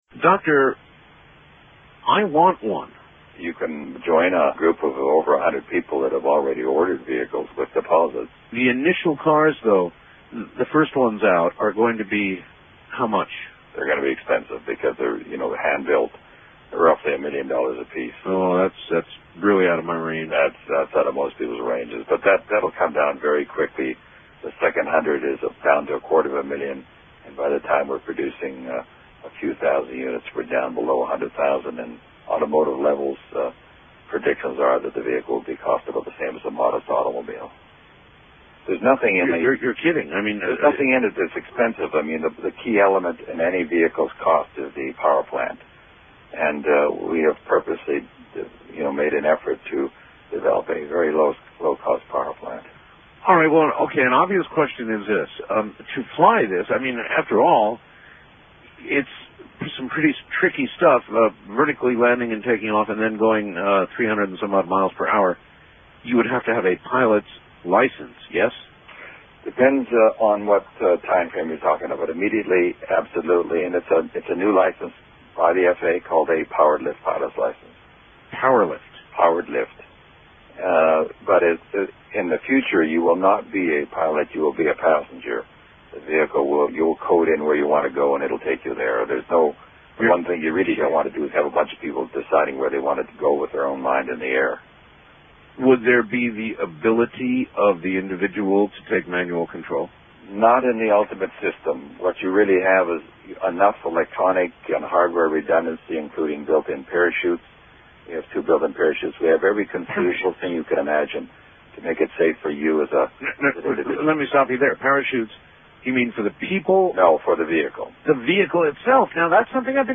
Interview 3